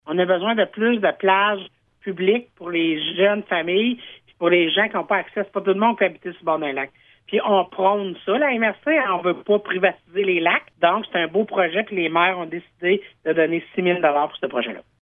Les maires de la Vallée-de-la-Gatineau ont accepté d’octroyer une somme de 6 000$. La préfète, Chantal Lamarche, donne plus de précision :